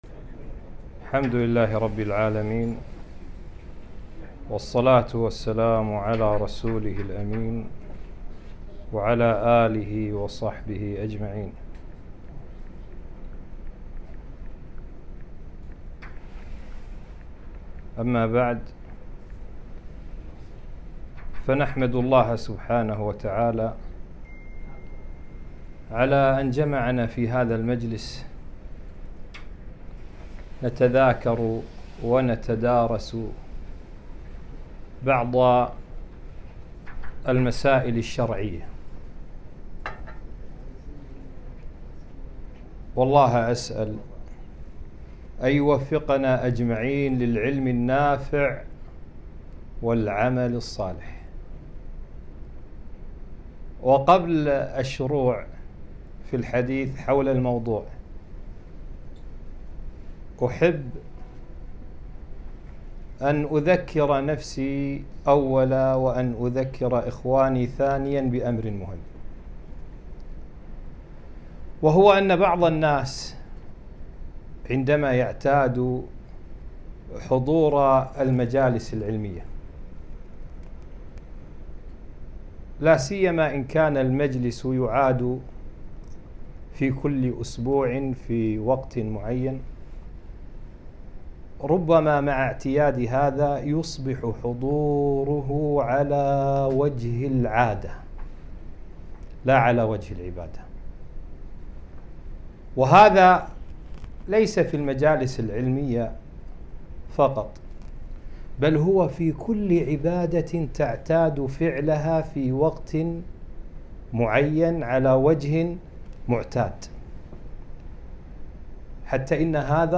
محاضرة - [ أنــواع الـتـوسُّــل ، وحــفــظ جـنـاب الـتـوحـيـد ]